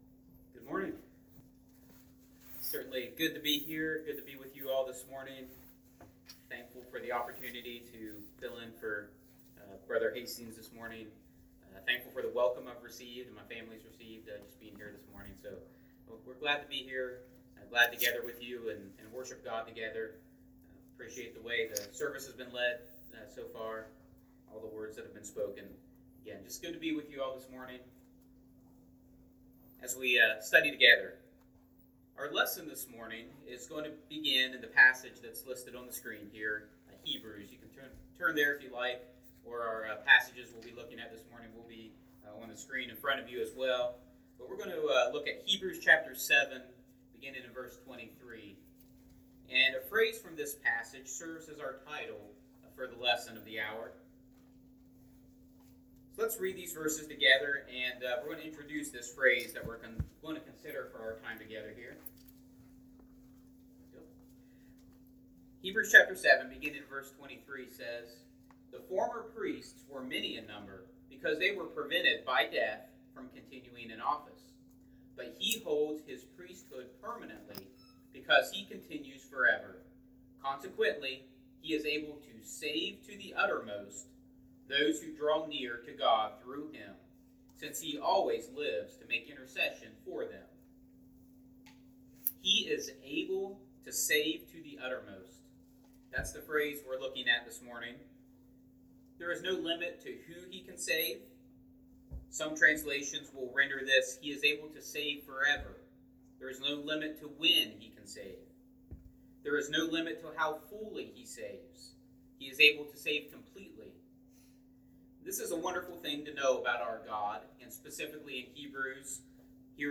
Passage: Hebrews 7:23-25 Service Type: Sunday AM God is Able to Save to the Uttermost!